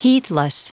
Prononciation du mot heathless en anglais (fichier audio)
Prononciation du mot : heathless